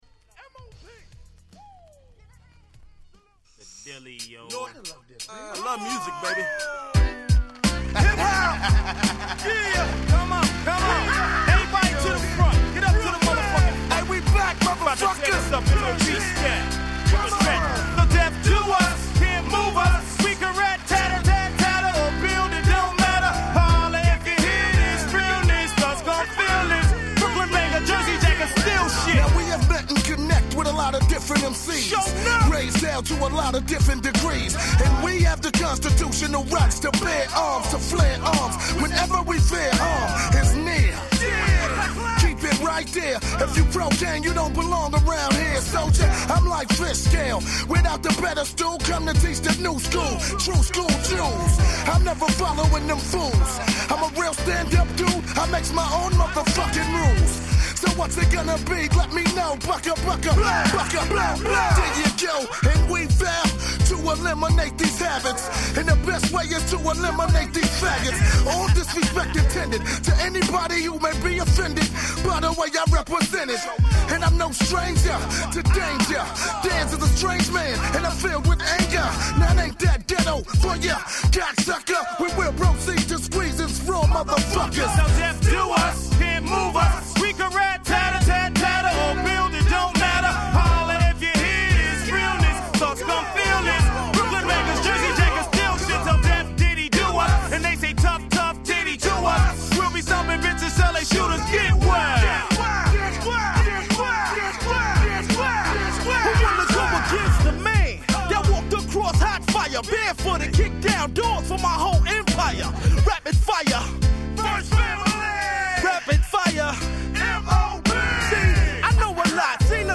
98' Smash Hit Hip Hop LP !!